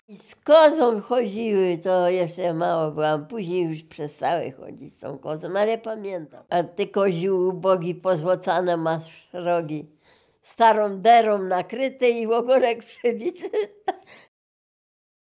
Dolny Śląsk, powiat bolesławiecki, gmina Nowogrodziec, wieś Zebrzydowa
Oracja